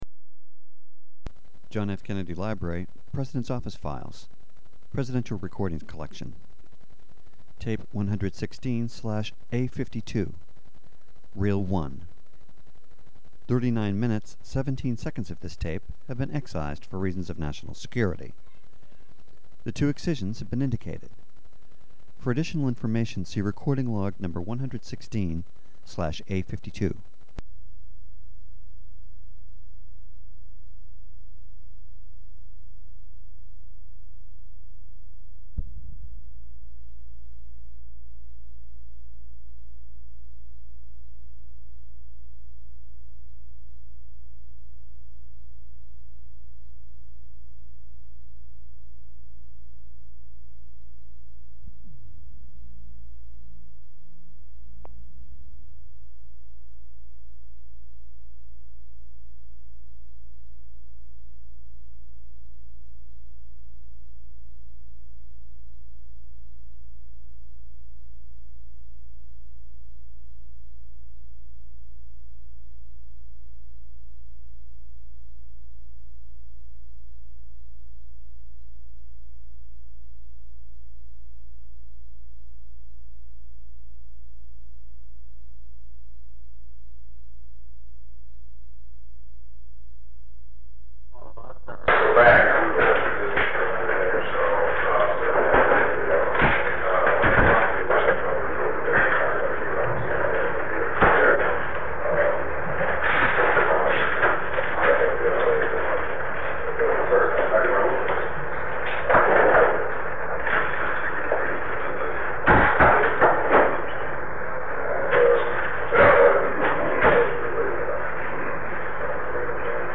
Secret White House Tapes | John F. Kennedy Presidency Meetings: Tape 116/A52.